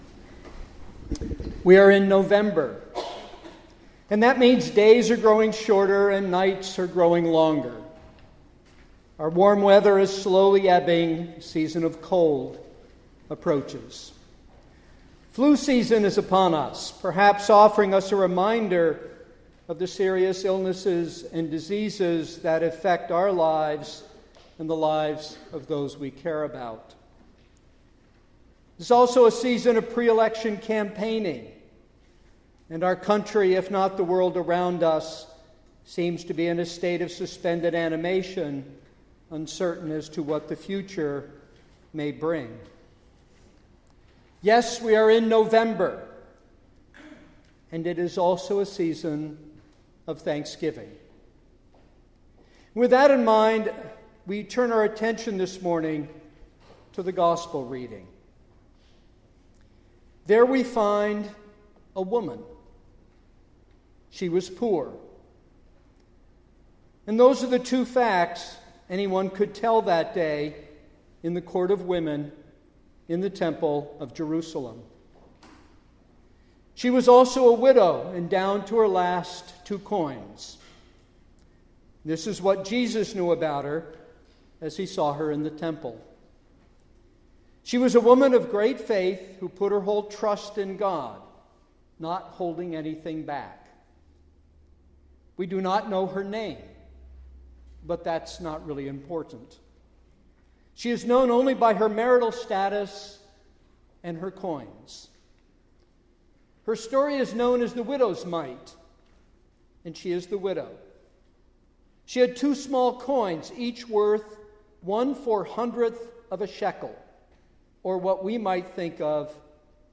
2015 Sermon